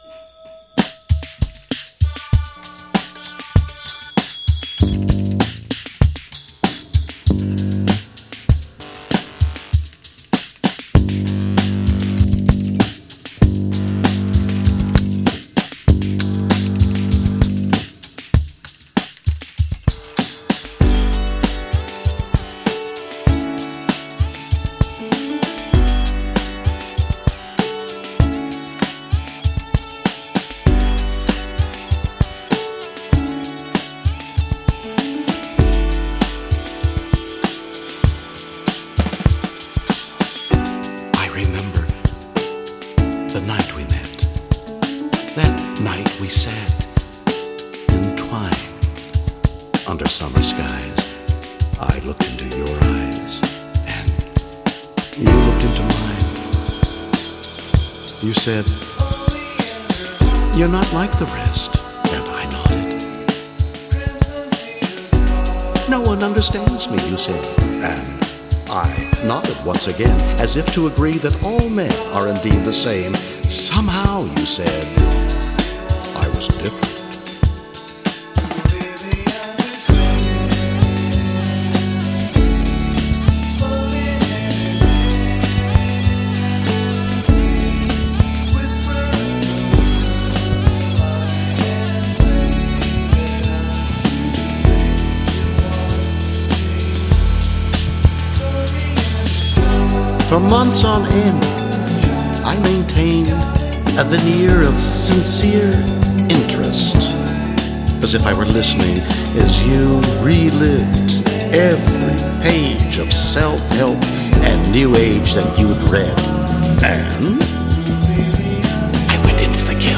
a collection of instrumental and spoken word music